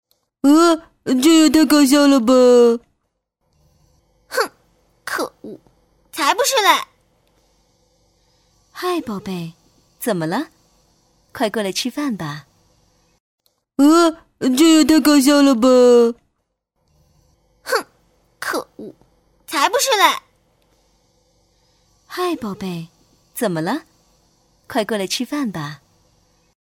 国语青年大气浑厚磁性 、沉稳 、娓娓道来 、亲切甜美 、女专题片 、宣传片 、旅游导览 、60元/分钟女S129 国语 女声 宣传片-旅游导览-导游词-【稳重古典文化意境】佛教圣地观音山 大气浑厚磁性|沉稳|娓娓道来|亲切甜美